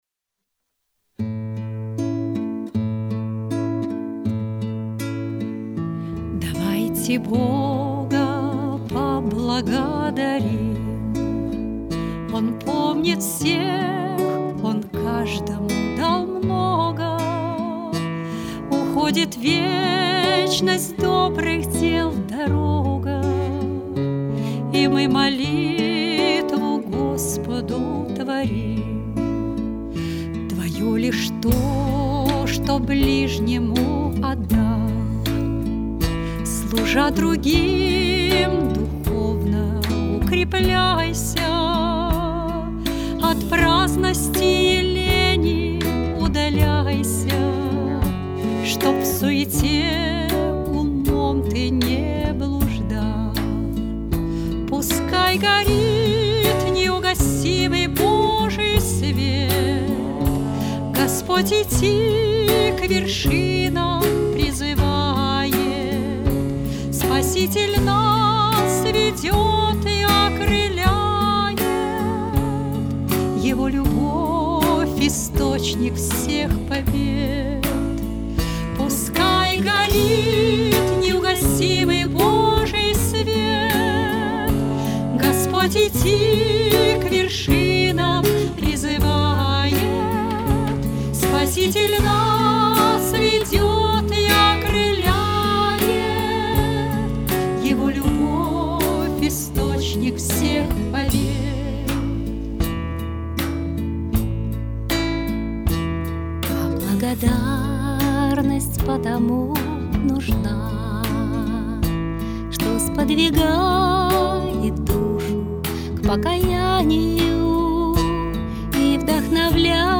Вот этой светлой и воодушевляющей песней мы и хотим поделиться с вами и надеемся, что она согреет вас в этот промозглый и сырой осенний вечер.
певчие сестричества храма святителя Николая у Соломенной Сторожки.